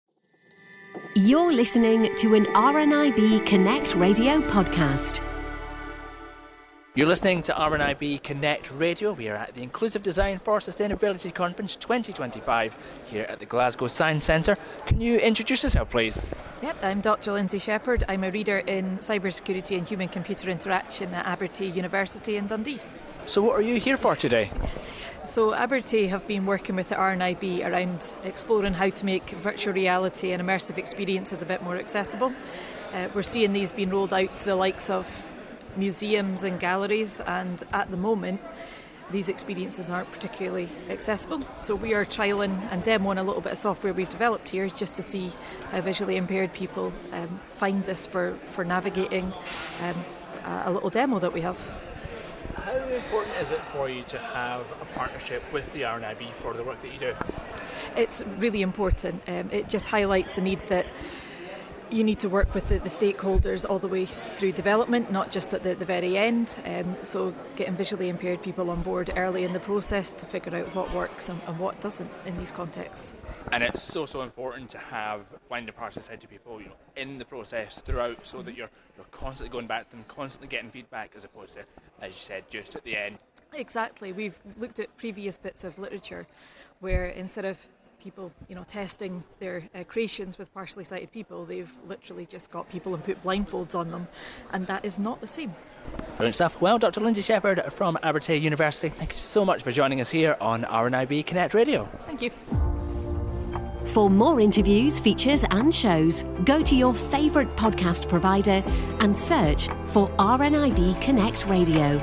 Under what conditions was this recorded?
More now from the Inclusive Design for Sustainability Conference